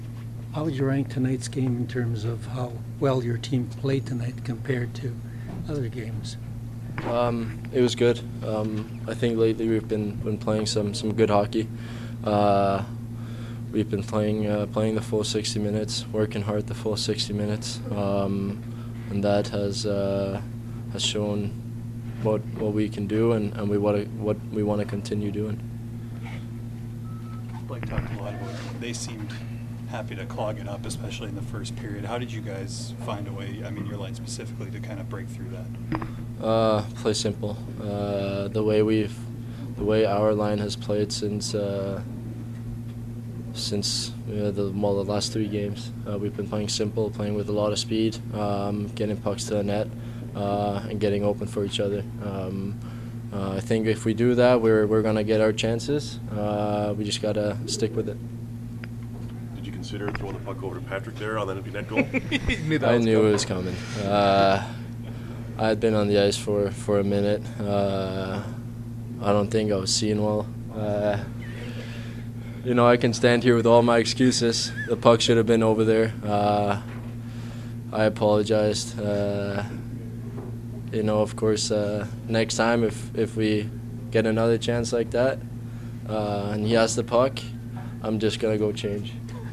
Post-game from the Jets dressing room as well as from Coach Maurice.
April-1-2017-Nikolaj-Ehlers-post-game.mp3